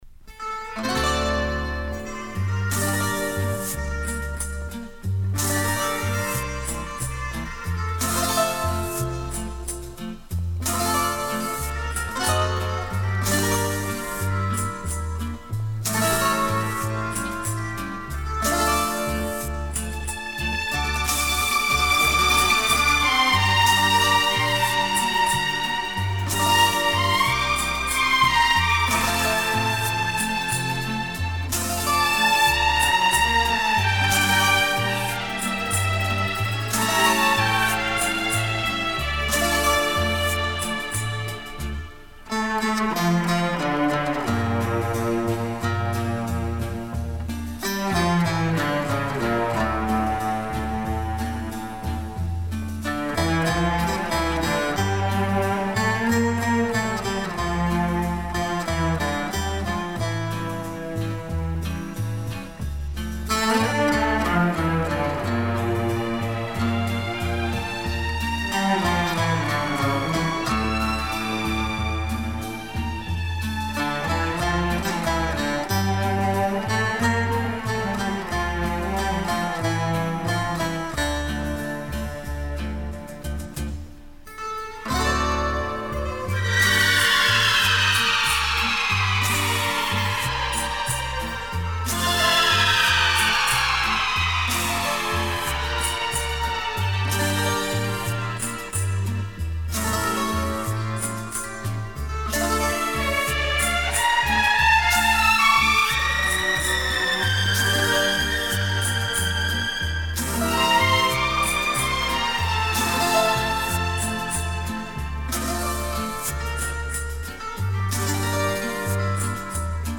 Gênero: Clássica